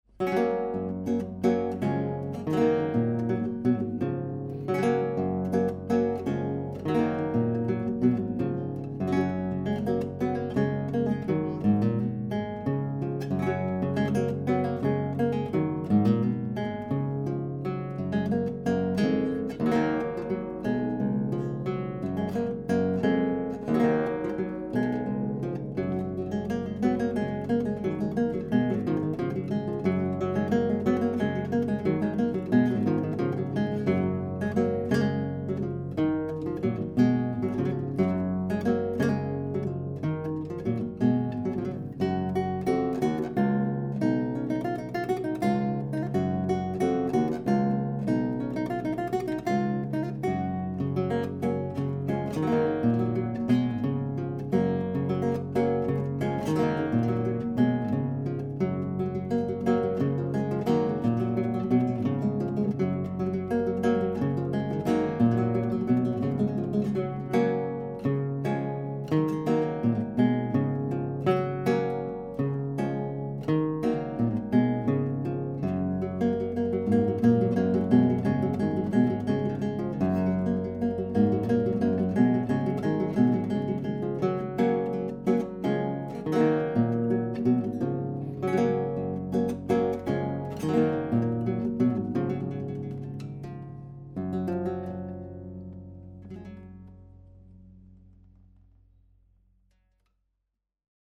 Auf Anfrage vom Tontechnikerforum 3db hier ein paar Samples, die die anerkannte Kombination CMC5 + MK2s von Schoeps mit dem vergleichsweise sehr günstigen Oktava MC012 mit Kugelkapsel vergleichen.
RME Fireface 800, Wohnzimmer, Abstand: ca. 0,5m, AB-Stereo mit Basis 30cm, keine Effekte oder EQ.
Chiaccona Schoeps MK2s
ChiacconaSchoepsMK2s.mp3